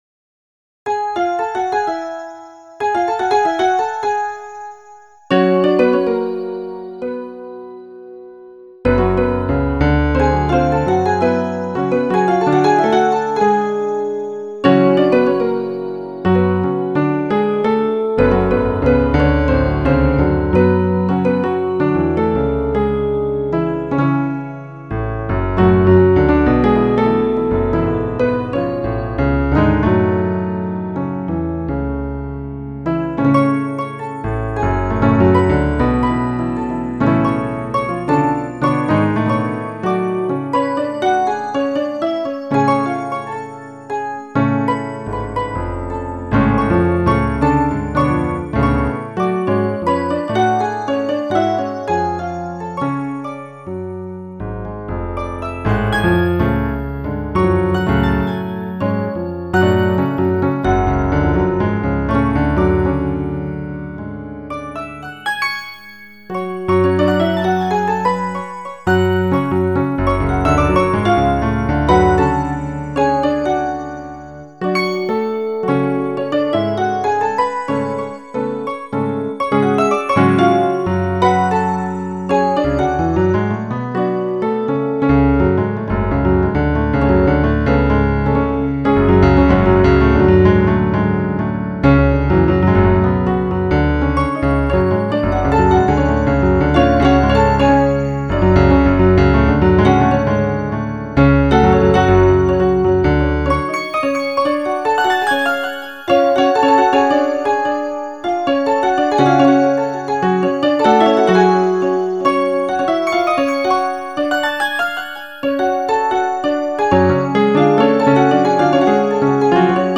Posted in Classical, Other Comments Off on